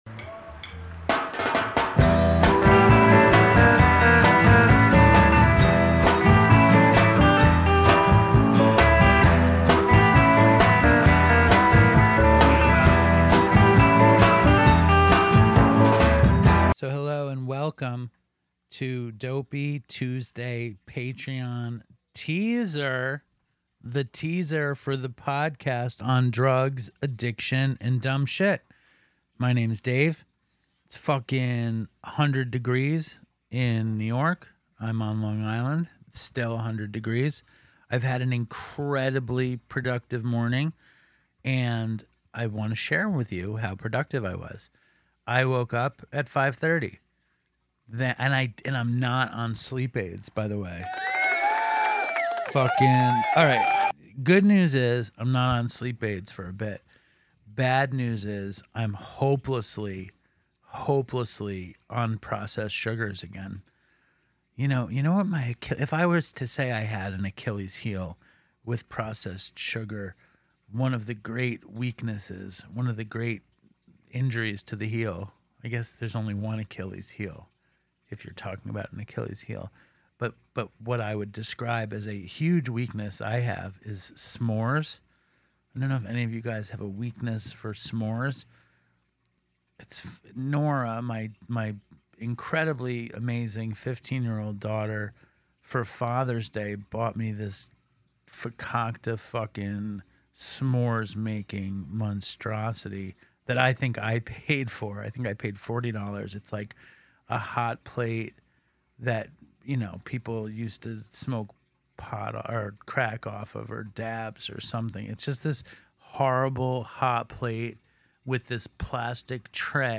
Comedy, Health & Fitness, Health & Fitness:mental Health, Mental Health, Alternative Health